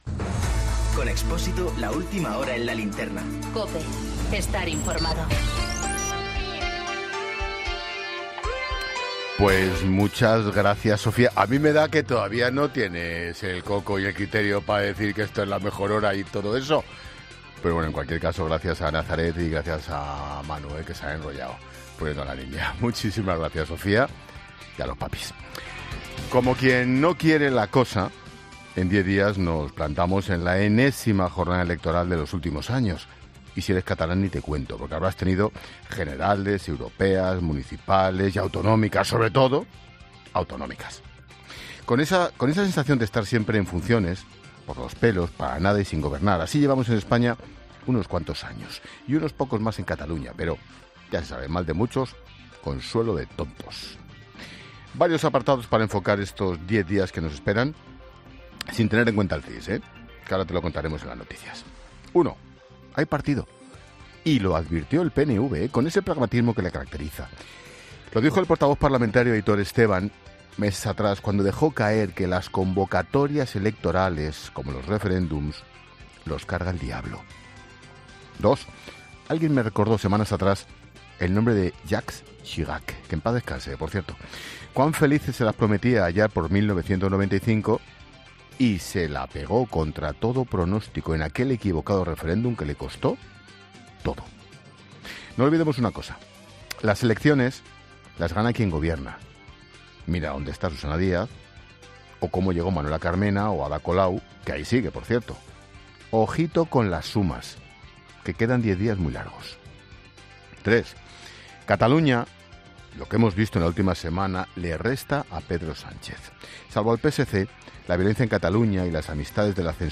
Monólogo de Expósito
El presentador de La Linterna analiza los datos del CIS de Tezanos que suben a Sánchez y bajan a Ciudadanos